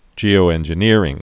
(jēō-ĕnjə-nîrĭng)